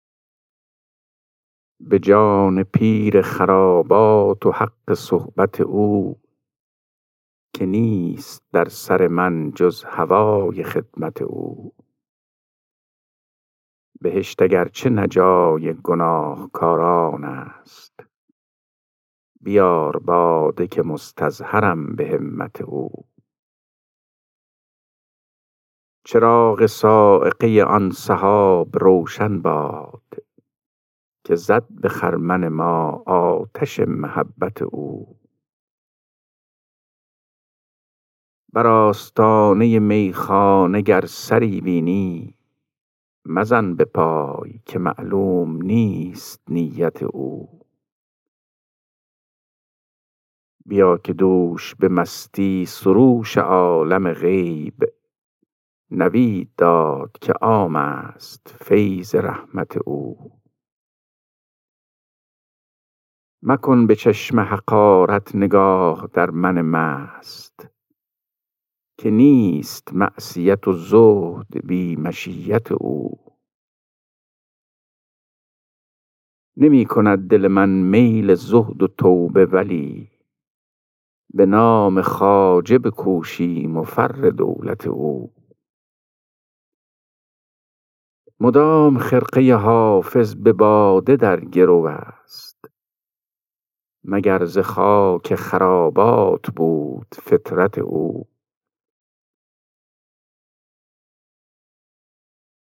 خوانش غزل شماره 405 دیوان حافظ